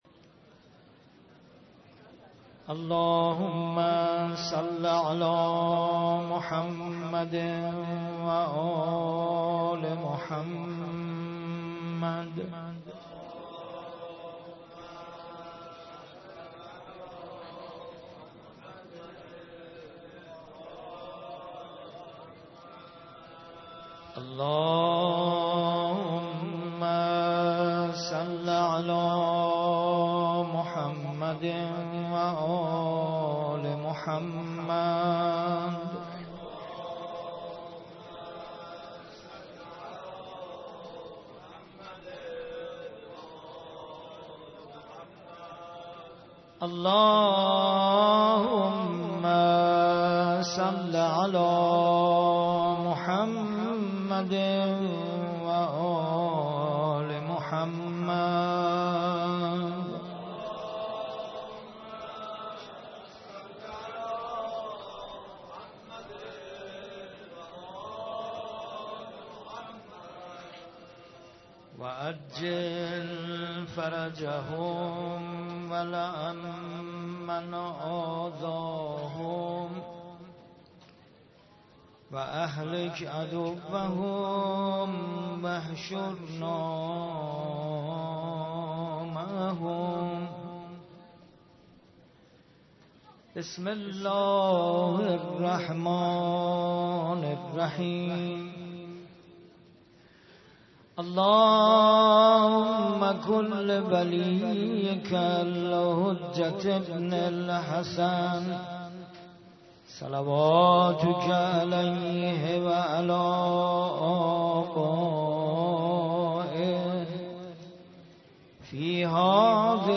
در مسجد شهدا برگزار گردید
قرائت دعای کمیل ، روضه حضرت عباس (علیه السلام)